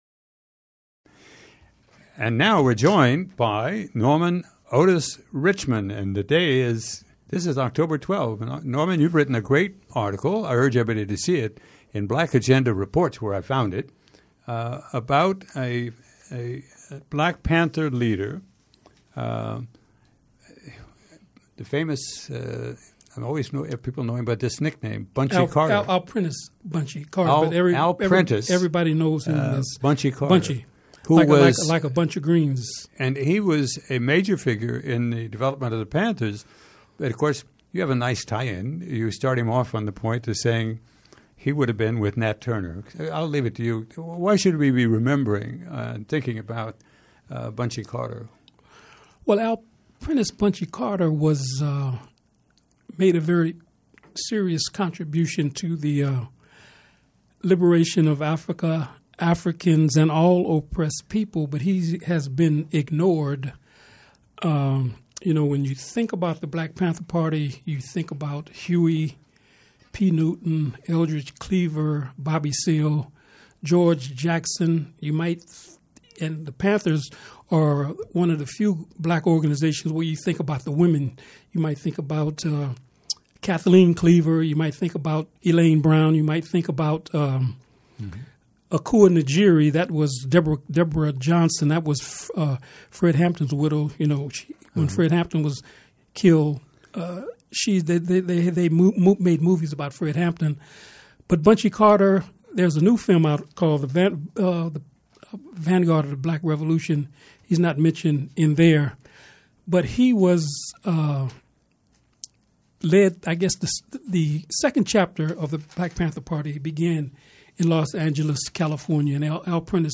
Program Type: Interview